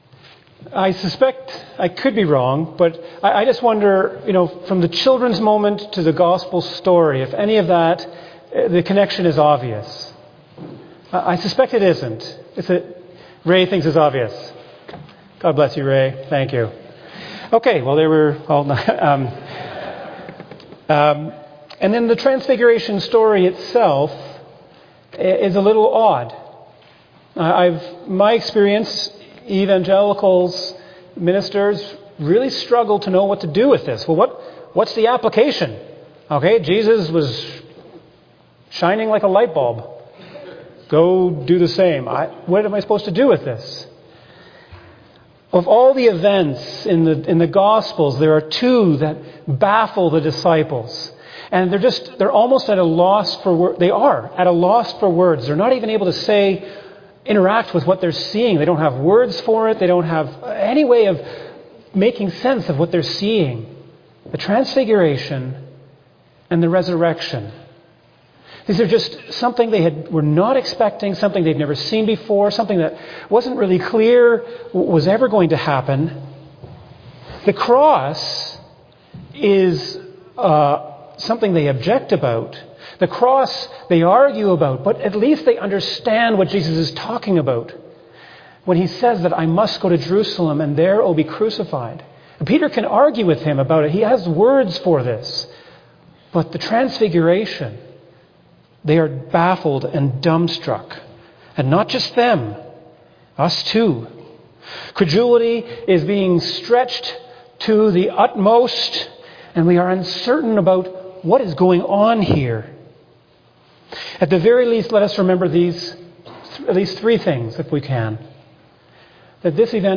2025 Sermon February 15, 2026